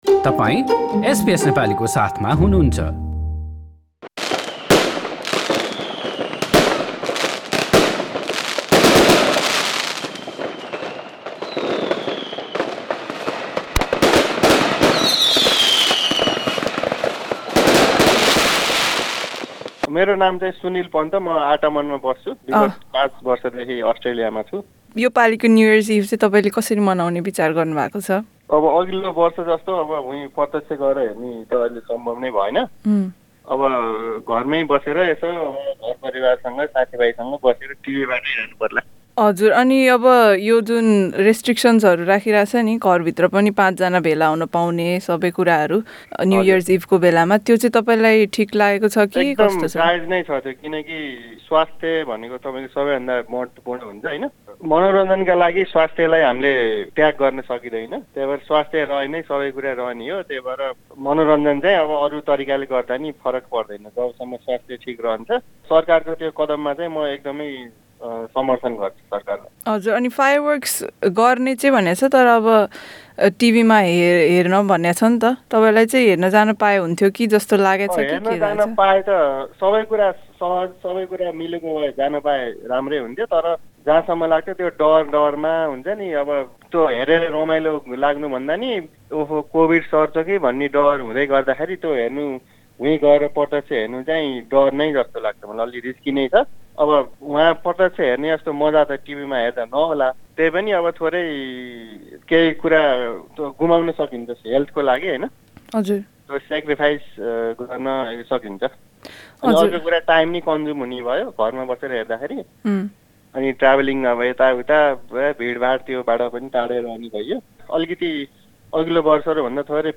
सरकारले यस पटकको नयाँ वर्षको फायरवोर्क्स घरमै बसेर टेलिभिजन मार्फत हेर्न अनुरोध गरेको छ । यो अवस्थामा सरकारको पछिल्लो निर्णय र न्यु यर्स ईभको योजनाका बारेमा नेपाली समुदायका सदस्यहरूले हामीसँग आफ्ना बिचार बाँडेका छन् । समुदायका सदस्यहरूसँग गरिएको कुराकानी सुन्न माथिको मिडिया प्लेयरमा थिच्नुहोस्।